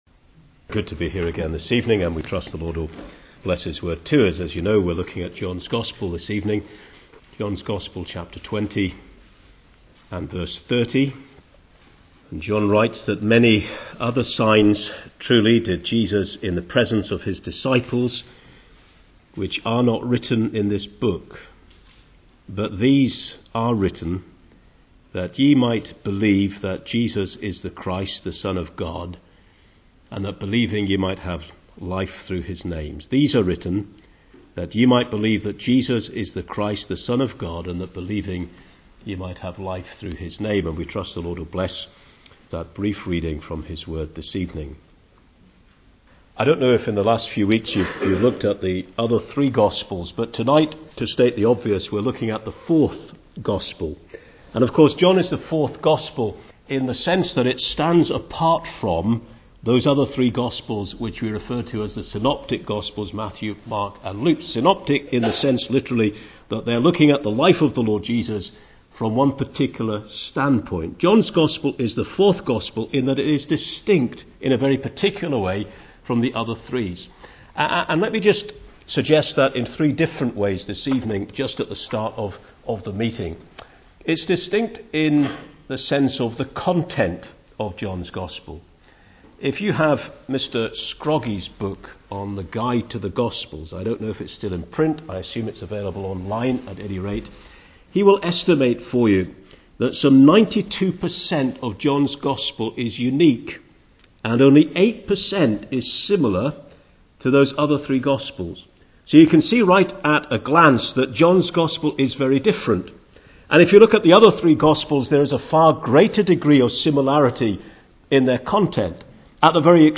He highlights John’s content, context and purpose as he sets out the themes and format of the 4th gospel. He speaks of Christ as the soul winner (Chs 1-4), the Shepherd (Chs 5-12), the School teacher (Chs 13-17) and the Sacrifice (Chs 18-21) before rounding off his message with a look at the glory of Christ in the gospel (Message preached 25th Feb 2016)